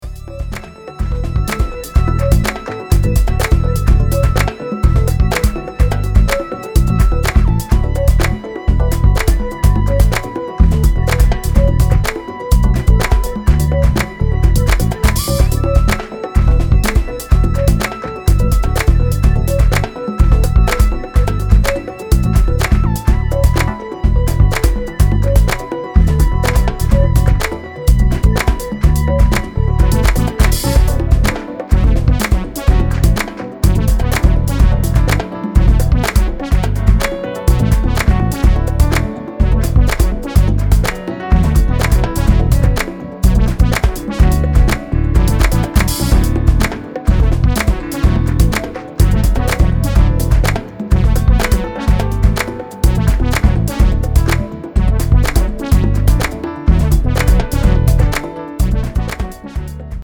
Fantastic broken beat from one of Japan’s rising stars.